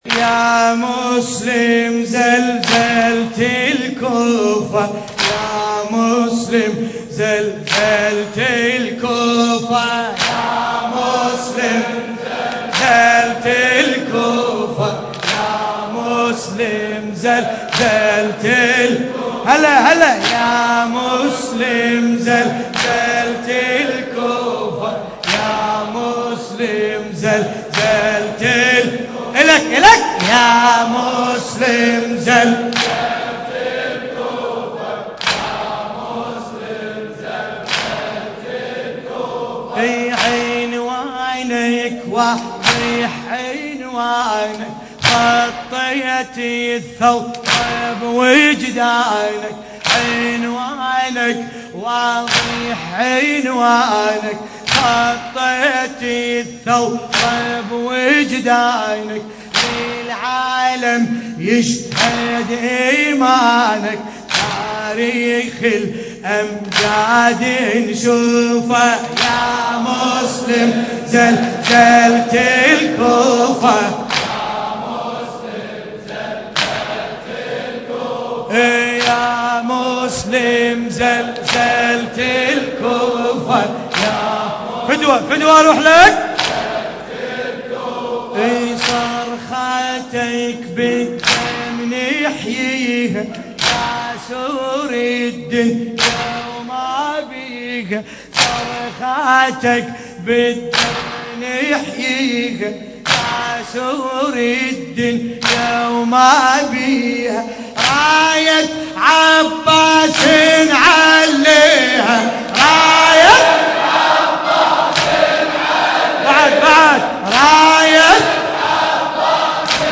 القارئ: باسم الكربلائي التاريخ: الليلة الخامسة من شهر محرم الحرام 1424 هـ - الكويت.